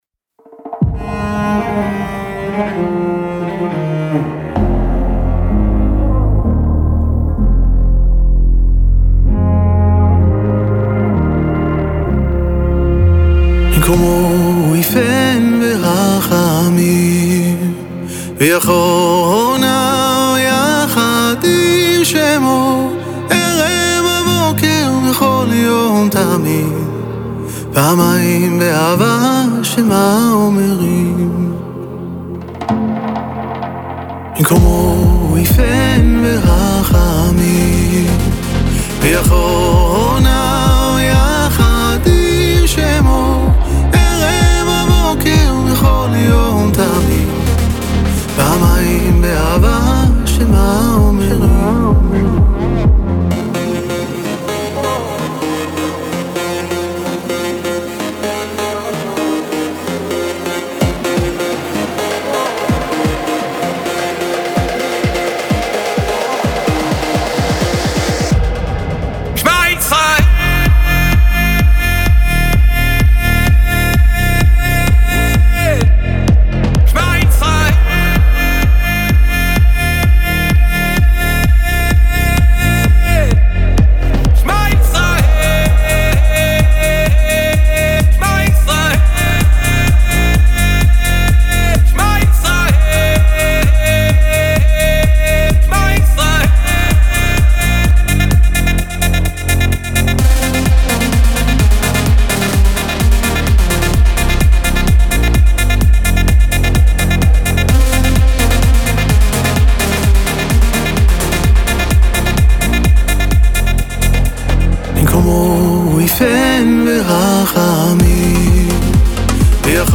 דואט מקפיץ וייחודי
שיר אמוני